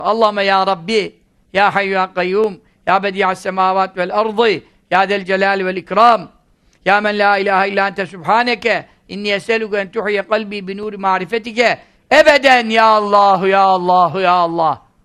Cübbeli Ahmet Hoca okuyor